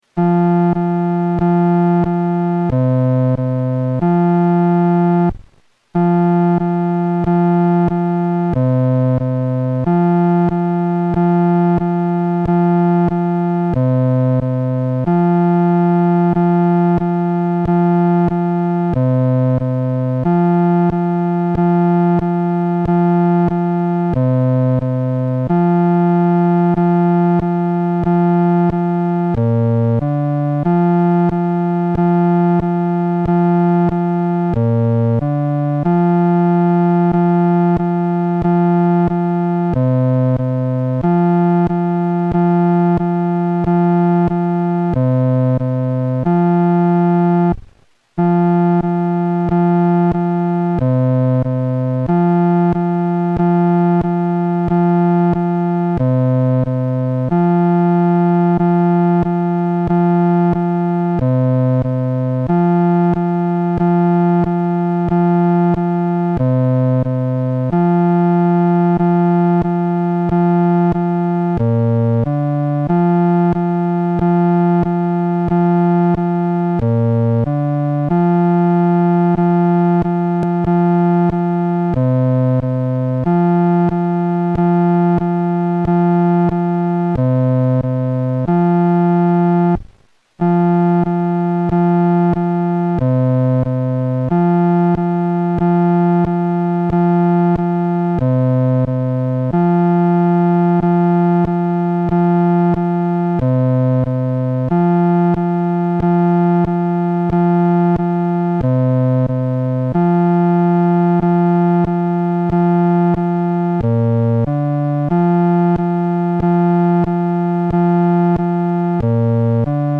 伴奏（男低音）